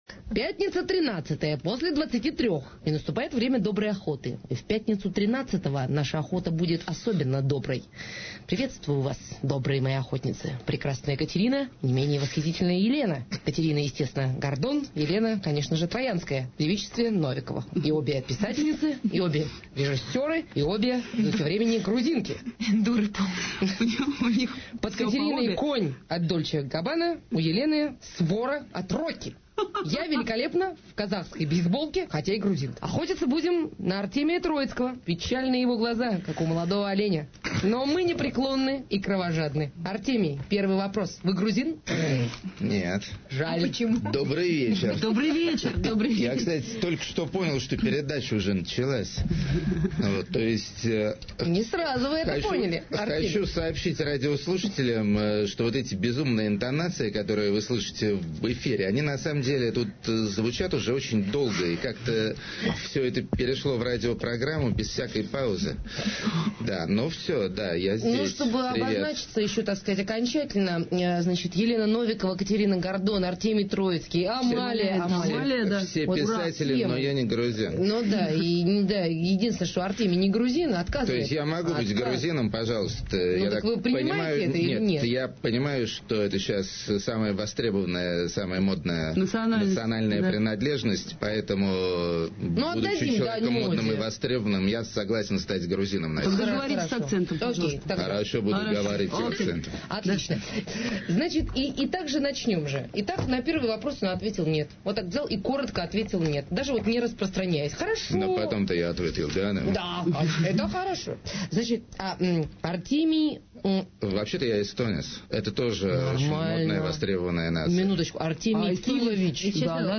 Интервью с АКТ | Радиоархив
Выкладываем недавнее интервью с АКТ на "Эхе". Оно не особенно интересное, потому что про музыку там немного,а ведущие всё время бормочут чепуху и не дают АКТ слова сказать.